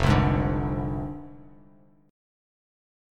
Fm6 Chord
Listen to Fm6 strummed